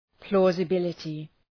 Προφορά
{,plɔ:zı’bılətı}